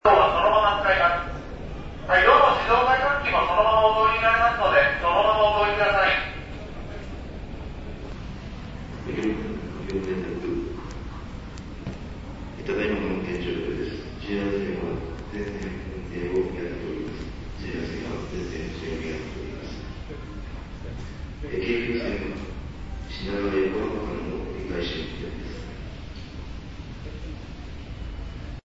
ちなみに都営線新橋駅改札では入場制限を行いつつ、電子改札は素通り状態で運用を行い、混乱を避けるため警察官も5名以上が配置されていてかなり物々しい雰囲気、
機能を放棄した電子改札の横では、駅員さんがこんなアナウンスを繰り返していました。